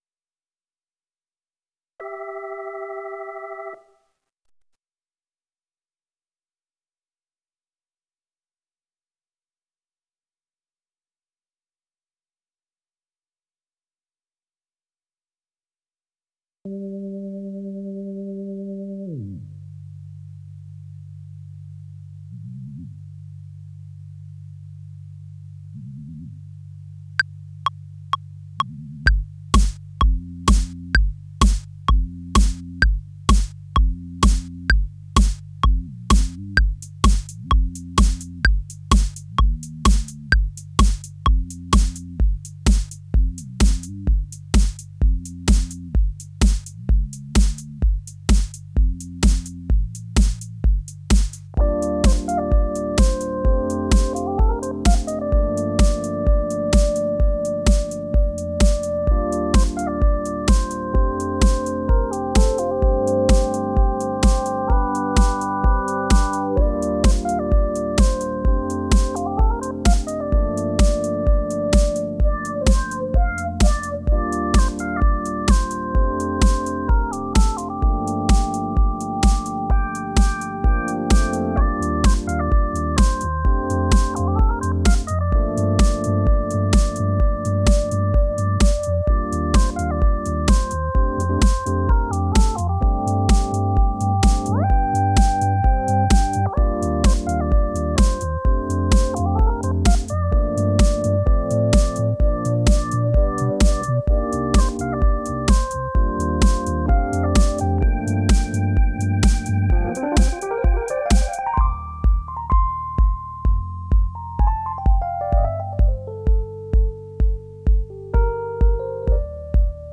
Vår vanliga set-up består av två keyboard, två mickar och en dator.
Inget är förinspelat!
• Jazzband
• Duo/trio